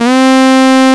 STRS C3 F.wav